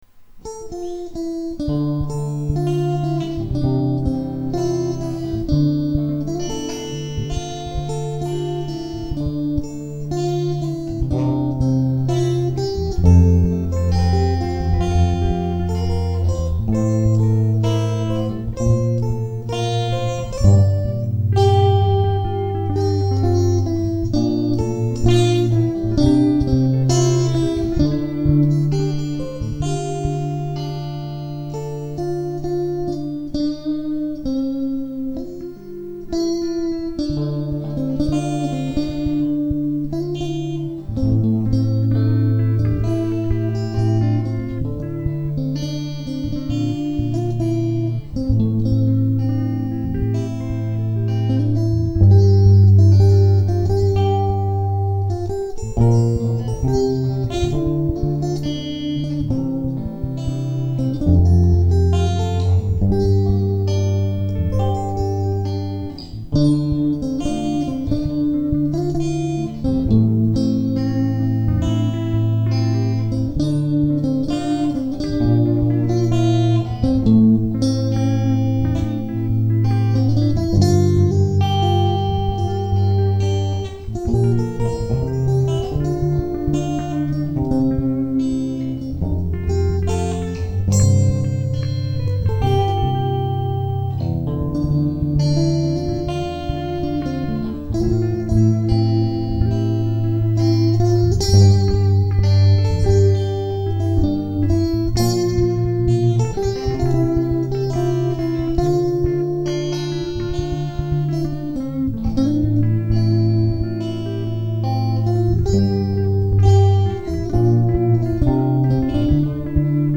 שקט -אבל יפה.
ההקלטה בסטריאו לא הוסיפה לשיר,חבל.
יש גם ערוץ של בס אם אני שומע נכון?